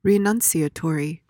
PRONUNCIATION:
(ri-NUHN-see-uh-tor-ee)